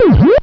eat_fruit.wav